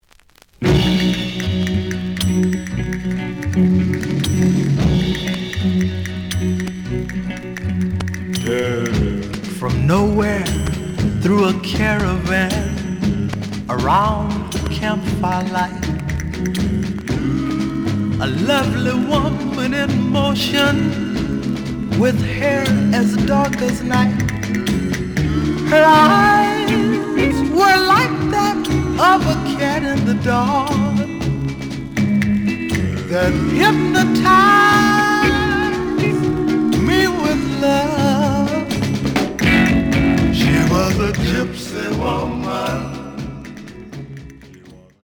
The audio sample is recorded from the actual item.
●Genre: Soul, 60's Soul
Some periodic noise on beginning of A side due to scrathces.